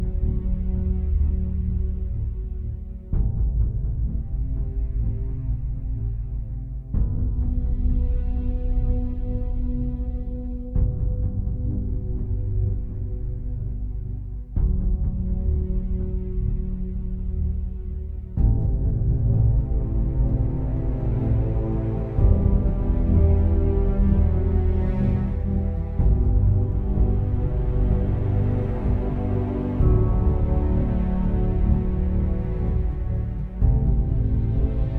Струнные и рояль
Жанр: Соундтрэки / Классика